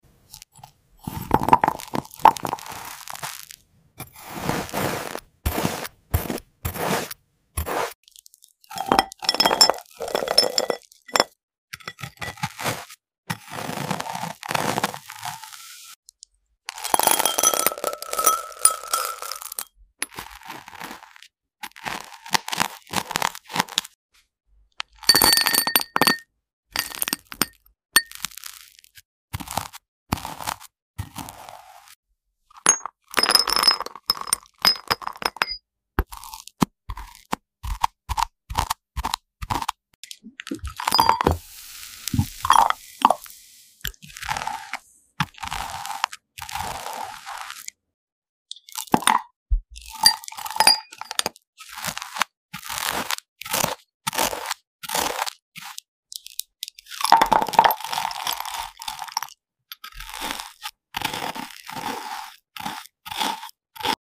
Upload By Satisfying Slices ASMR